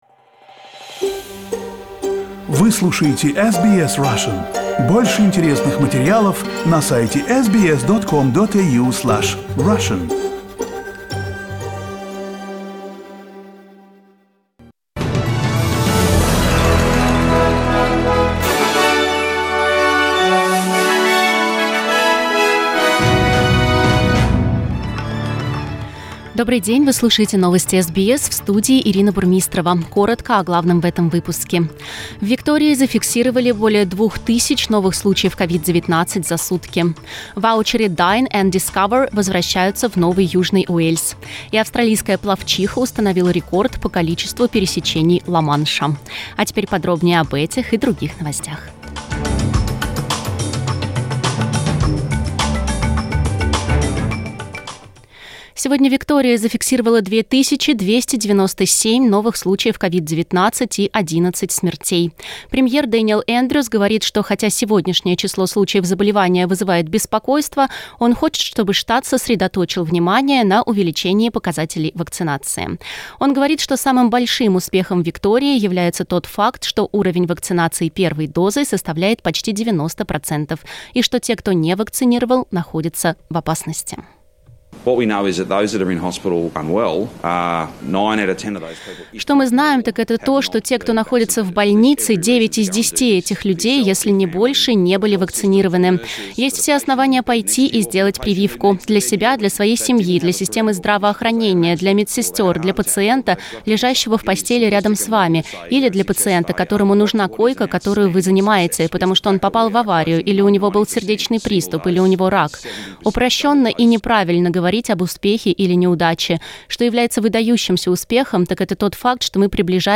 SBS news in Russian - 14.10